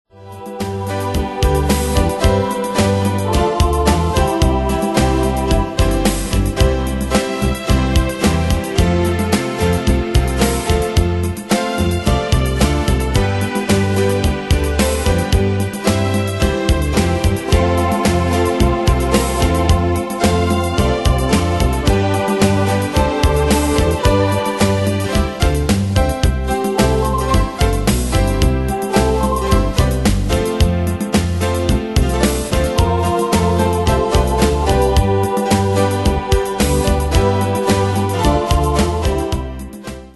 Style: PopAnglo Année/Year: 1984 Tempo: 110 Durée/Time: 4.54
Pro Backing Tracks